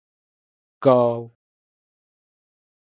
Ausgesprochen als (IPA) /ɡaʊ/ Etymologie (Englisch) From Scottish Gaelic gobha (“smith”).